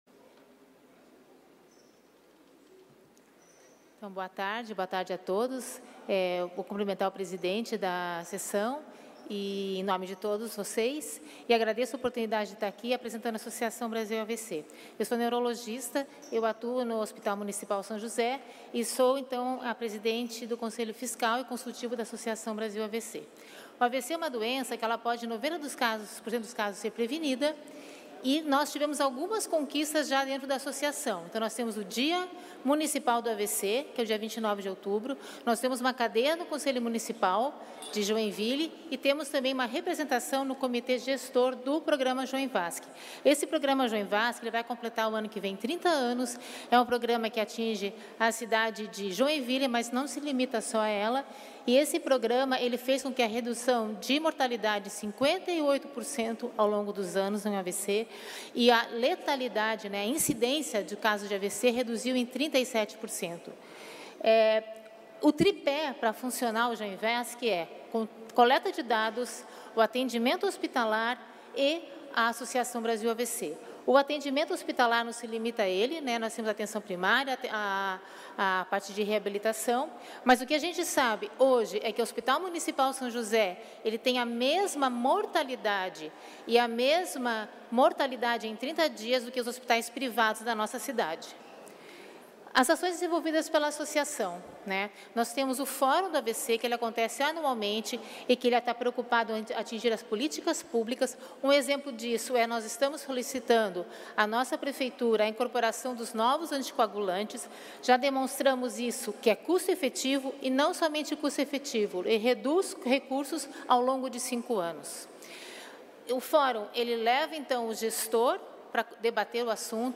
Pronunciamentos das entidades da região Norte na sessão ordinária desta terça-feira (4)